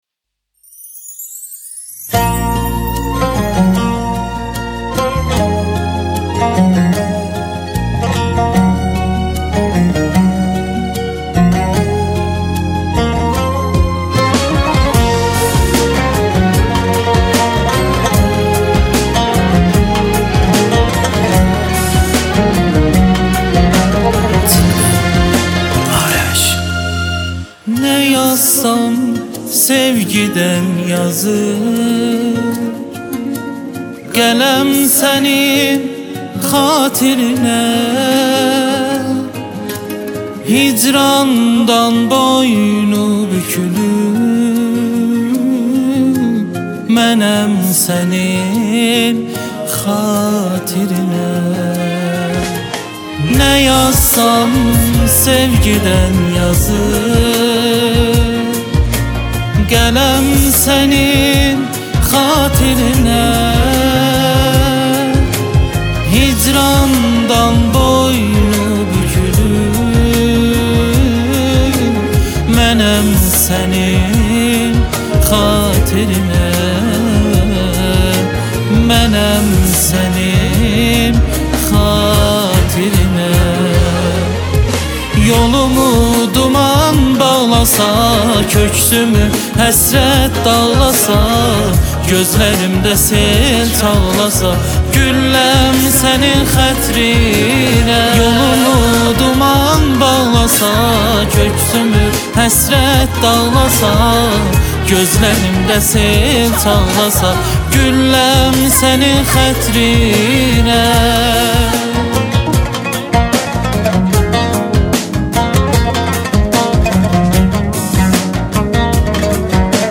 اهنگ شاد ترکی